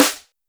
Snares
Snare36.wav